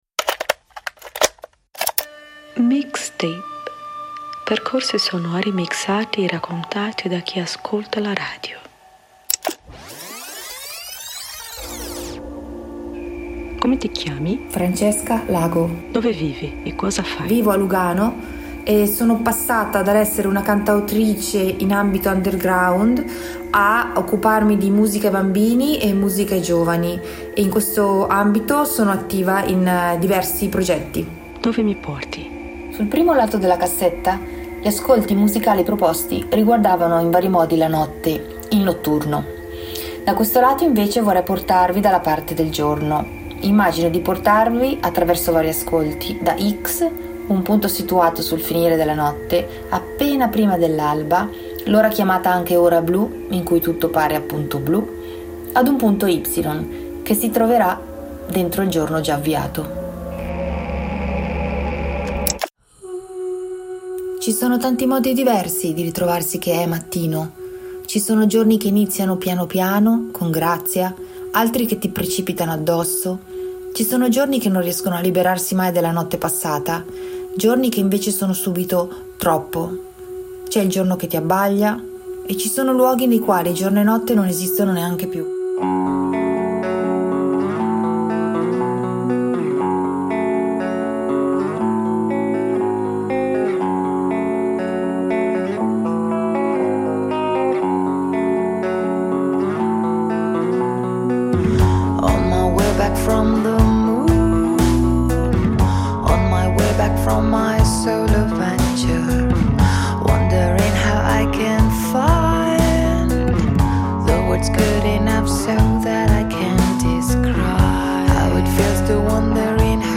Percorsi sonori mixati e raccontati da chi ascolta la radio